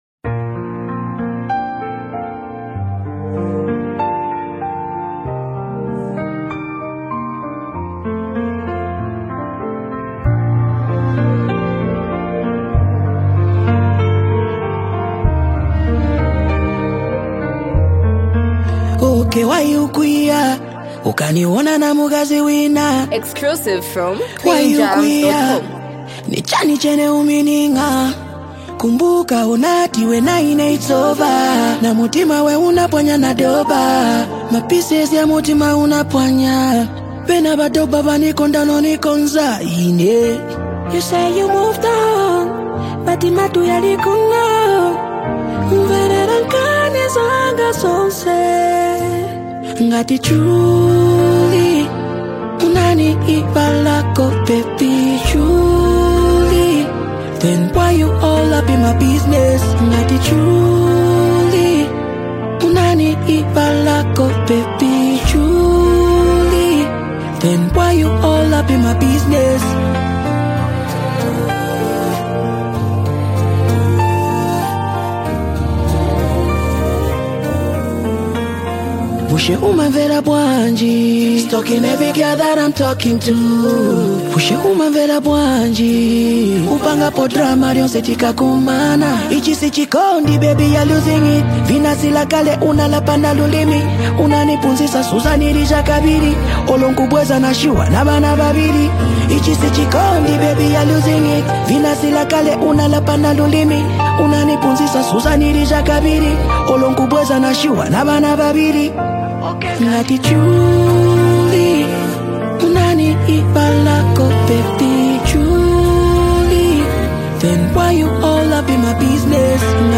heartfelt love song
passionate delivery and catchy melody